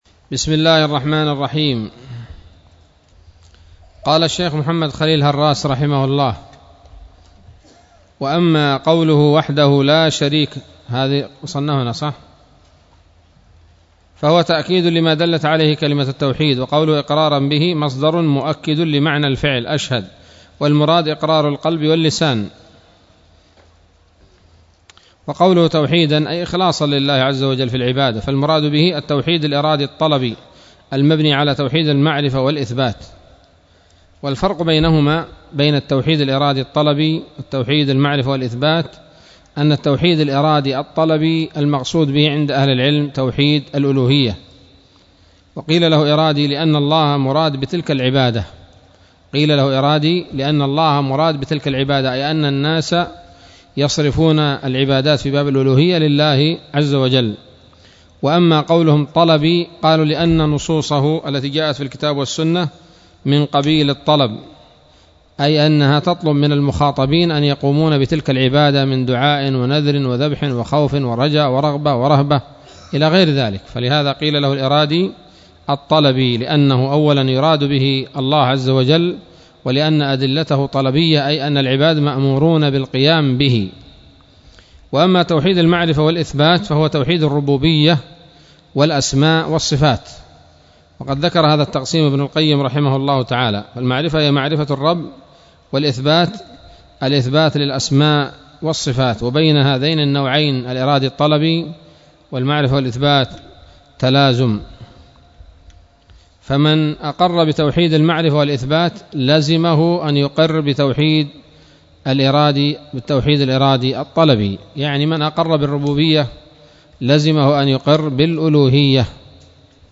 الدرس الرابع عشر من شرح العقيدة الواسطية للهراس